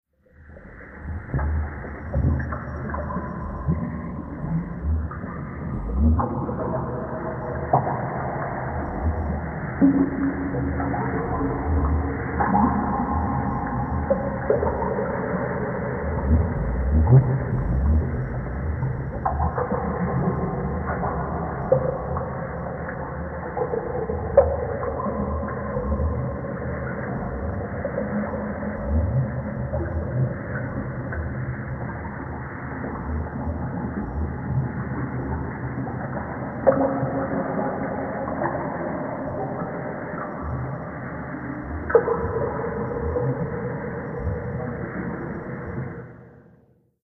WATER-UNDERWATER FX SCUBA: Bottom of the ocean, deep underwater.